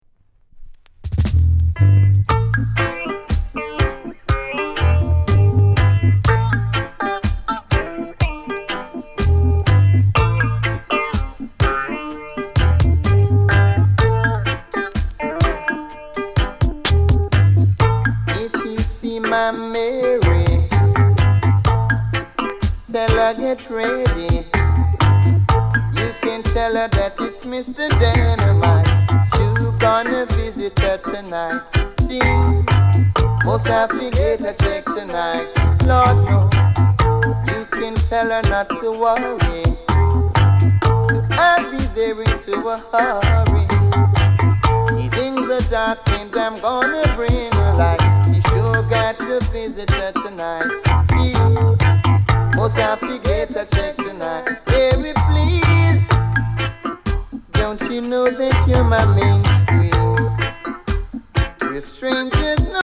ROOTS